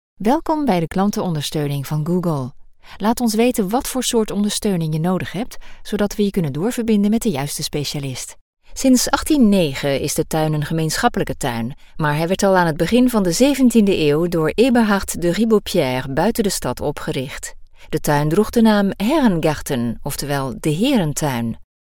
Grabaciones en nuestro estudio de sonido asociado de Holanda.
Locutoras holandesas